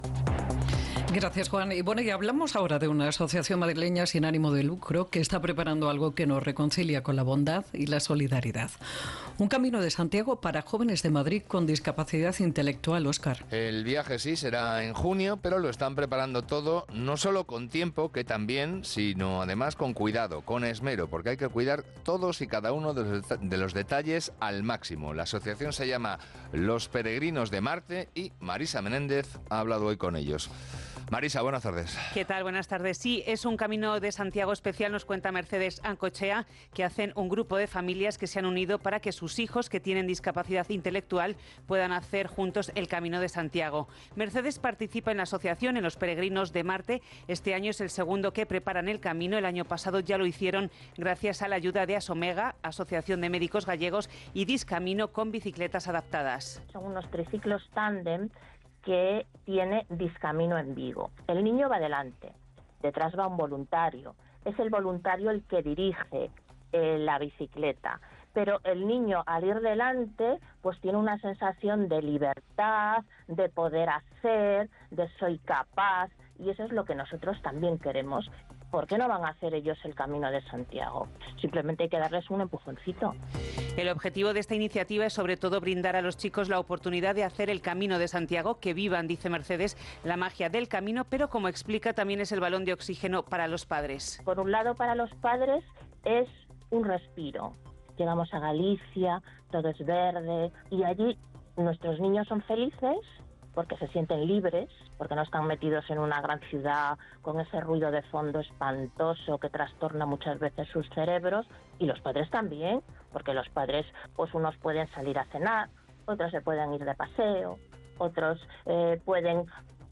Las ediciones madrileñas de dos de los programas insignia de Onda Cero, "Más de uno" y "La brújula", han recogido esta semana información sobre la iniciativa más destacada de la asociación Los Peregrinos de Marte, con la que colabora Asomega Axuda: la ruta jacobea adaptada a sus integrantes, niños y jóvenes con discapacidad.